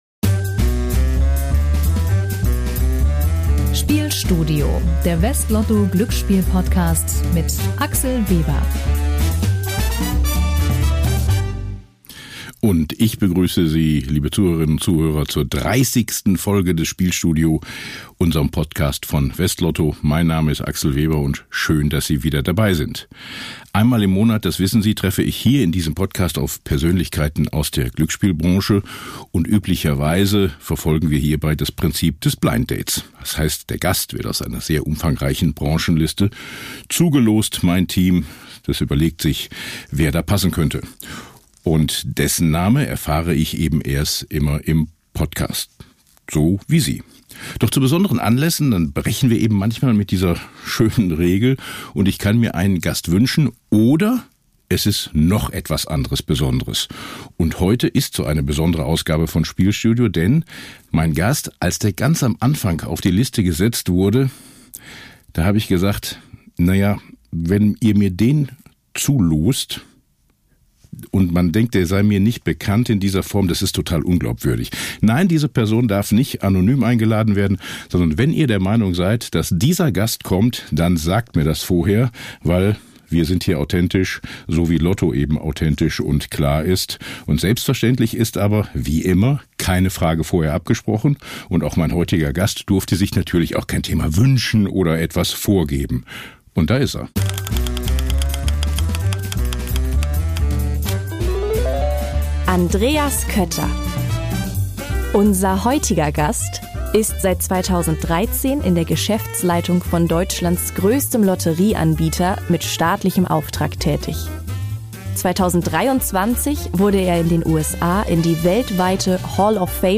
In unserer Spezialfolge des Glücksspiel-Podcasts hören Sie ein Gespräch über persönliche Motivation, das Phänomen der Jackpot-Fatigue, die Herausforderungen nationaler Märkte, Synergien zum Eurojackpot und einen Blick in die Glaskugel: Wird es eine Weltlotterie geben?